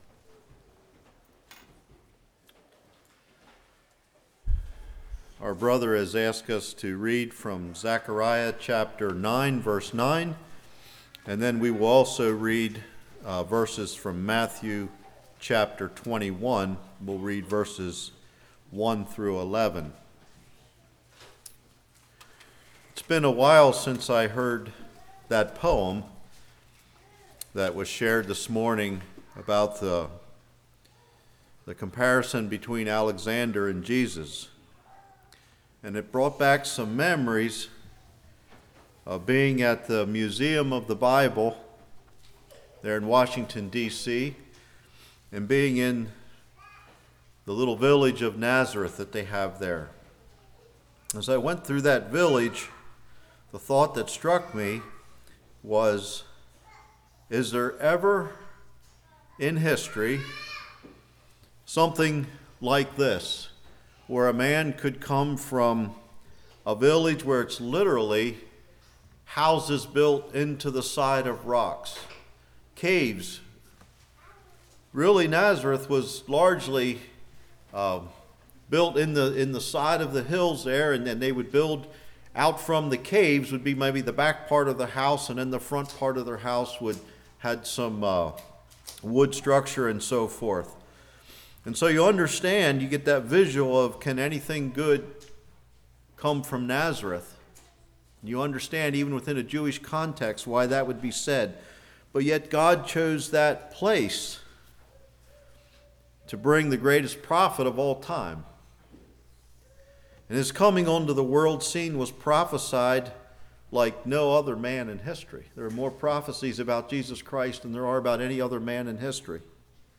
Matthew 21:1-11 Service Type: Revival When Will It Happen?